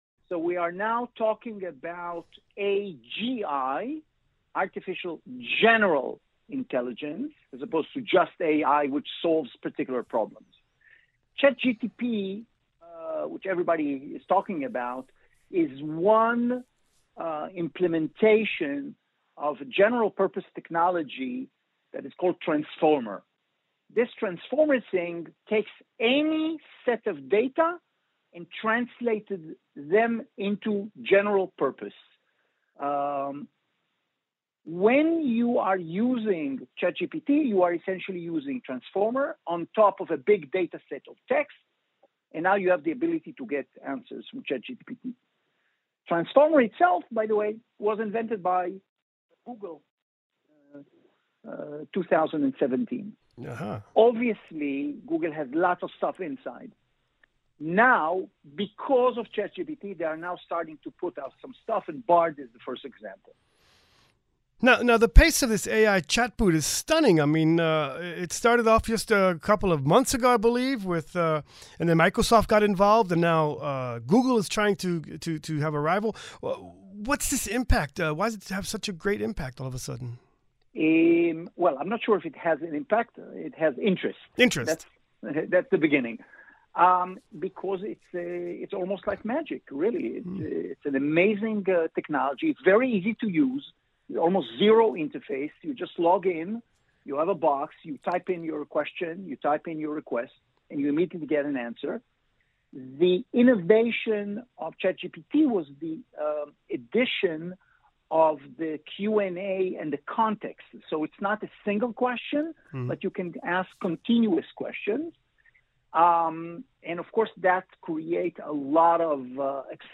Radio Reka Interview: The AI Chatbot Revolution